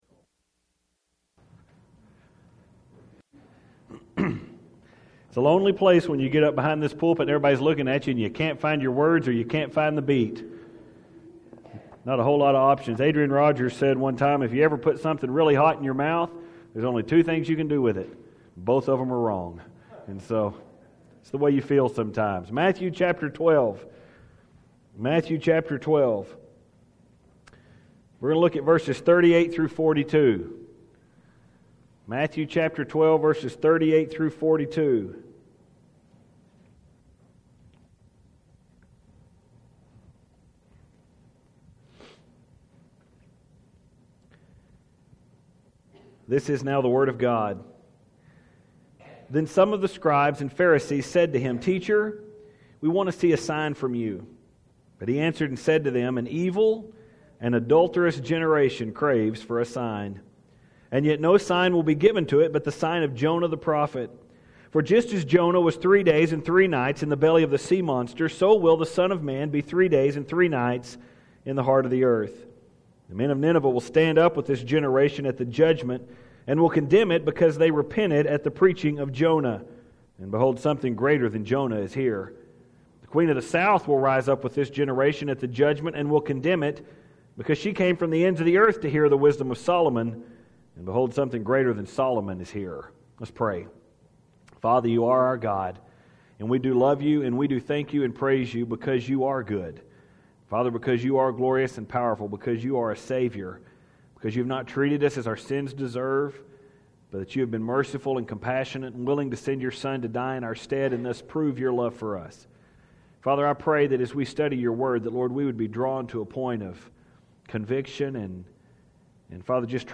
After reading the text, I suppose the point of the sermon is obvious.